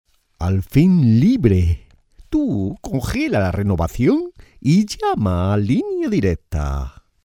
Línea Directa.  Voces para el nuevo spot y nuevos anuncios.